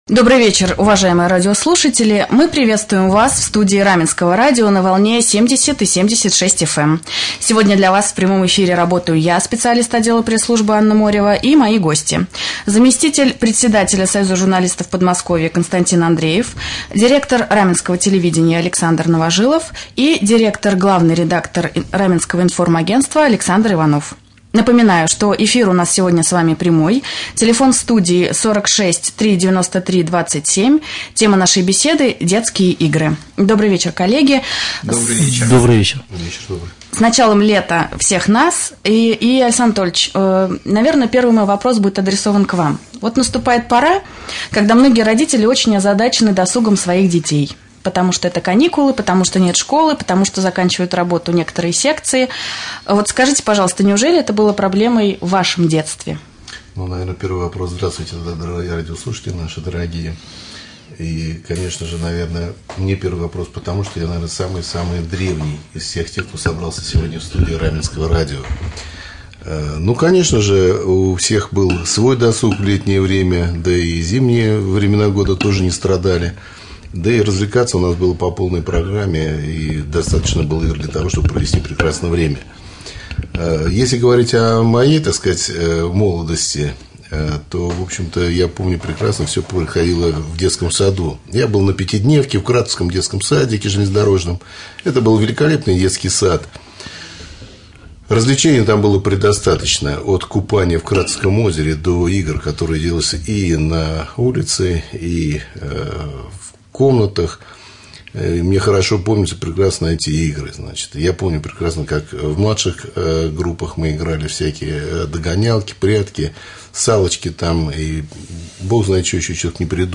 Гости студии
2.Прямой-эфир.mp3